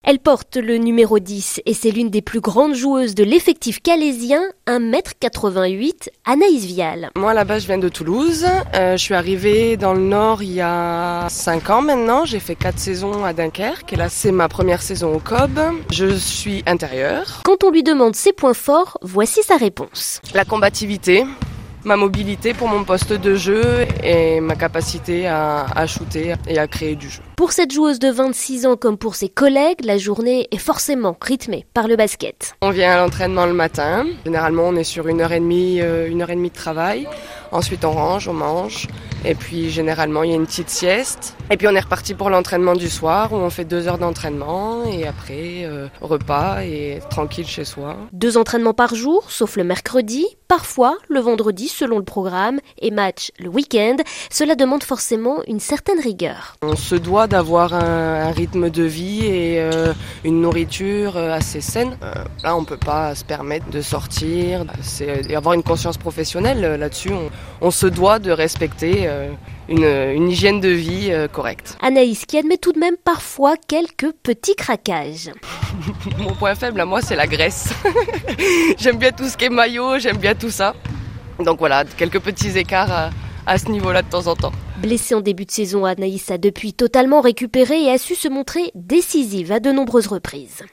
lors d'un entraînement à la salle Calypso